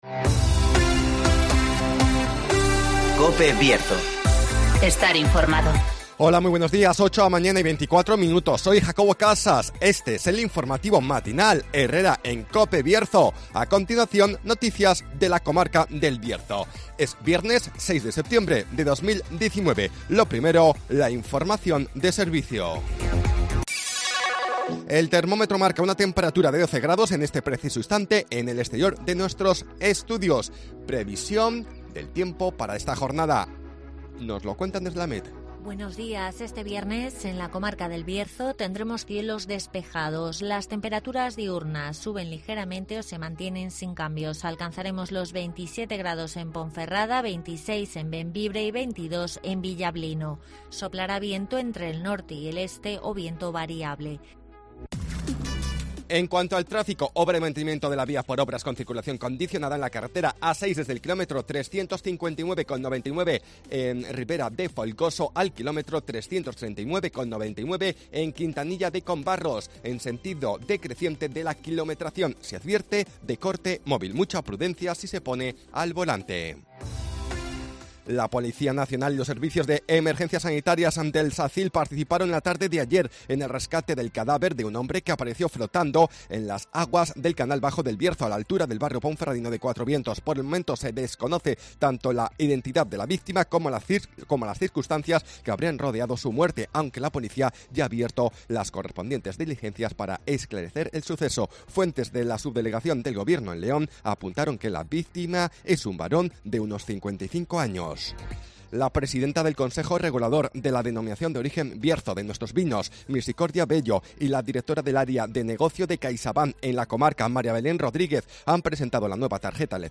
INFORMATIVO
-Conocemos las noticias de las últimas horas de nuestra comarca, con las voces de los protagonistas